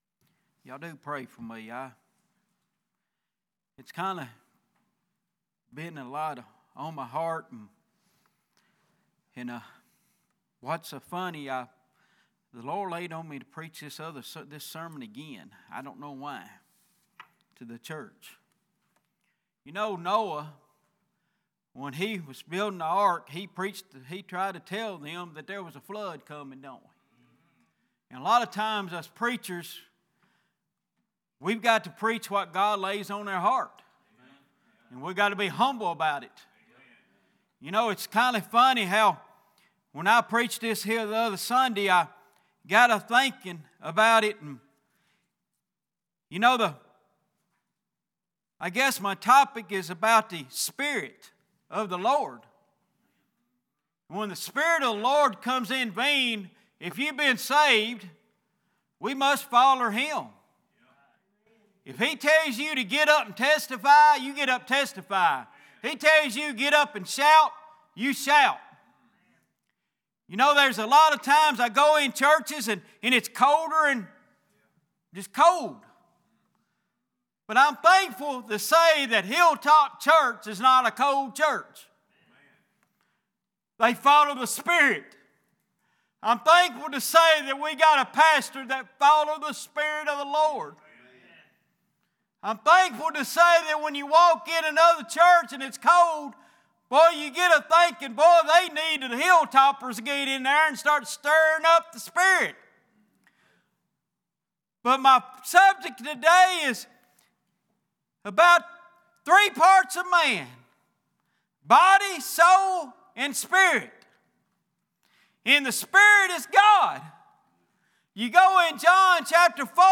Series: Sunday Evening